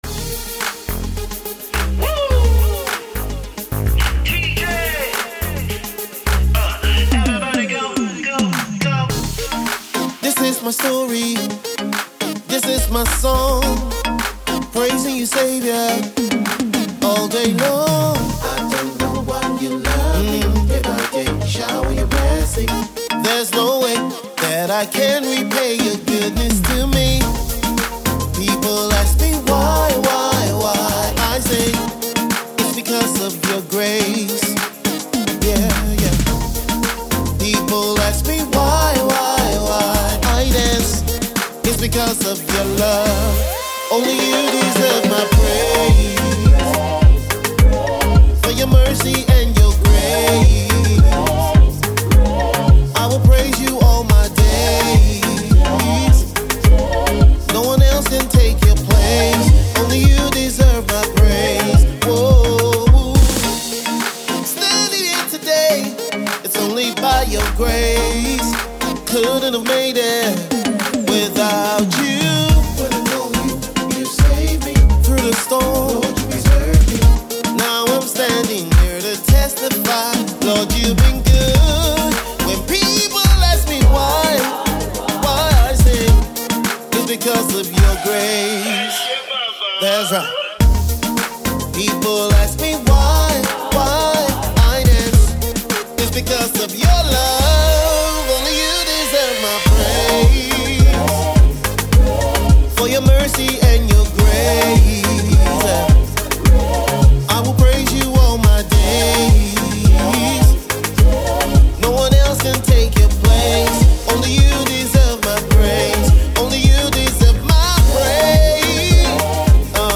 uplifting gospel song
background vocals